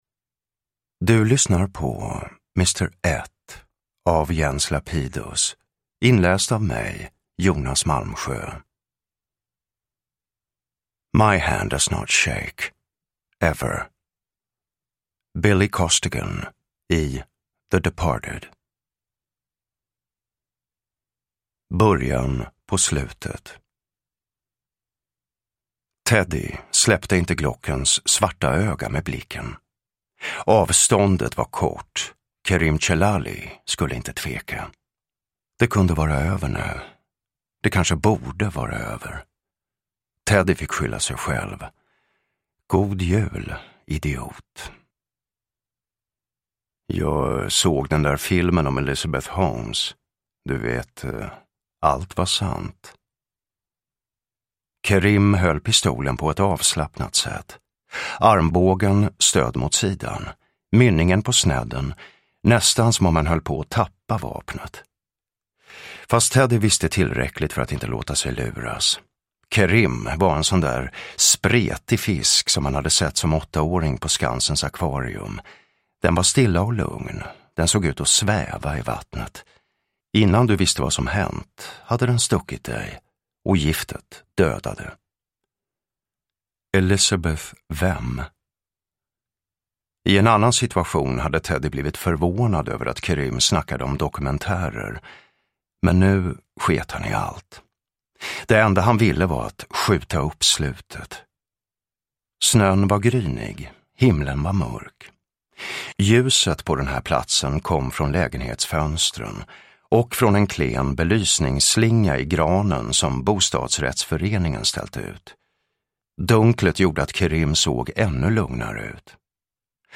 Mr Ett – Ljudbok – Laddas ner
Uppläsare: Jonas Malmsjö